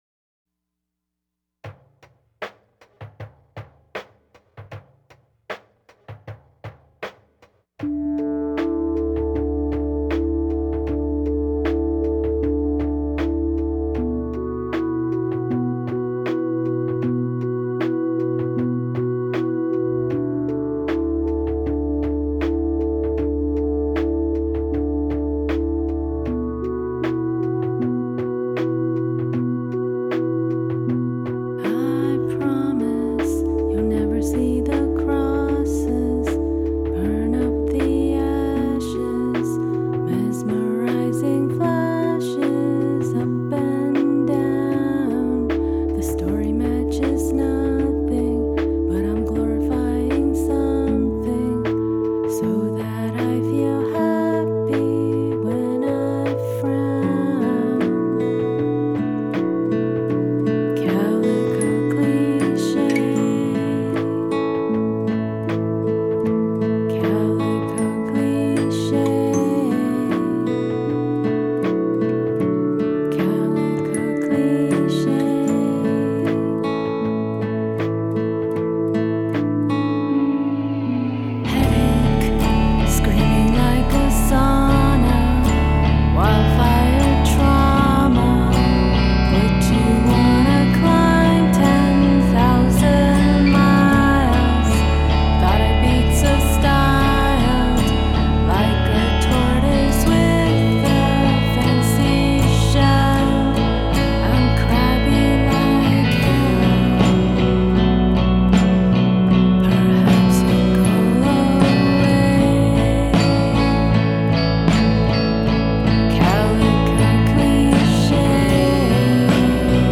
pop-folk